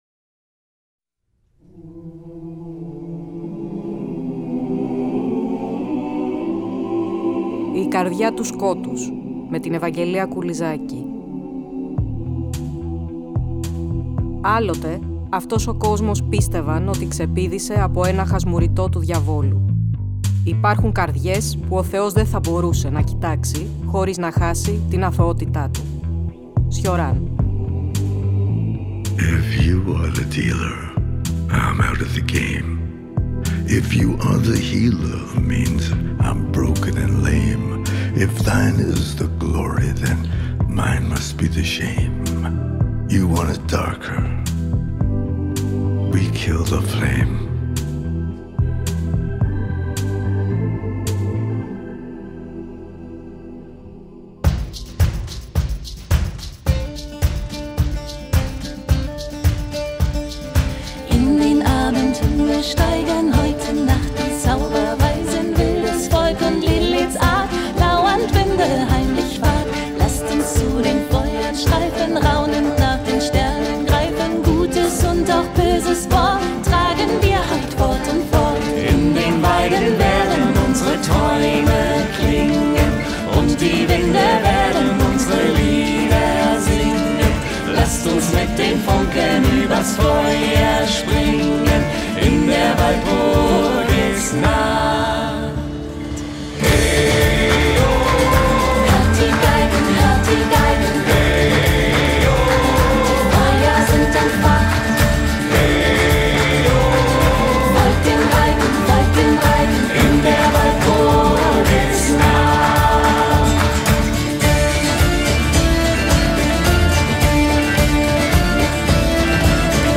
Ακούστε το 13ο επεισόδιο του τρέχοντος νέου Κύκλου της εκπομπής, που μεταδόθηκε την Κυριακή 30 Νοεμβρίου από το Τρίτο Πρόγραμμα.